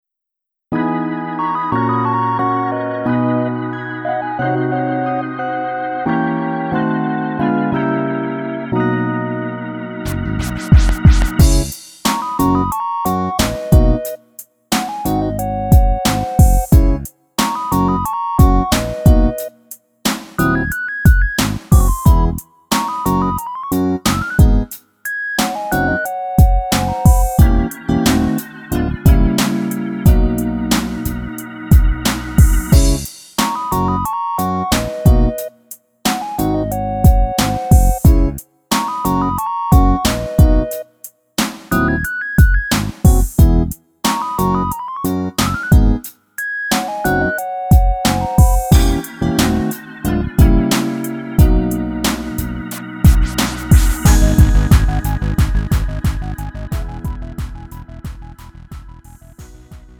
음정 -1키 3:46
장르 가요 구분 Lite MR
Lite MR은 저렴한 가격에 간단한 연습이나 취미용으로 활용할 수 있는 가벼운 반주입니다.